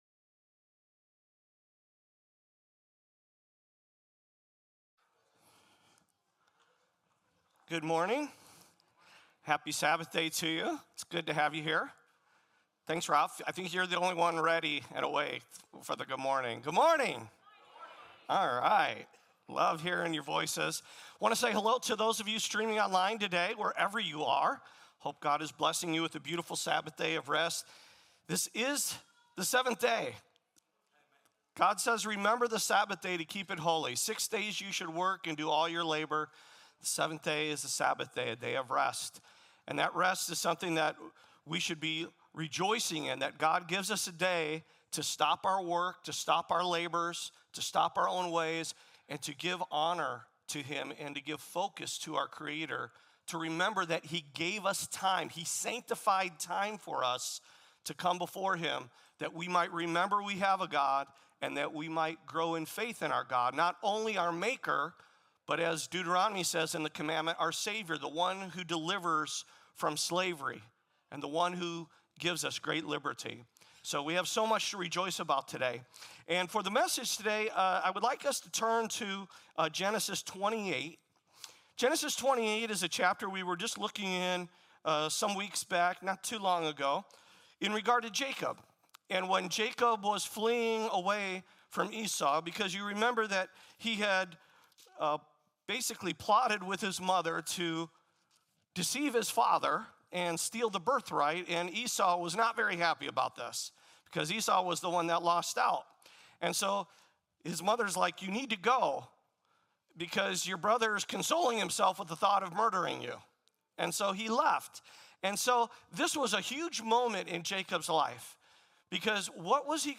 The Faithful Partner - Sabbath Christian Church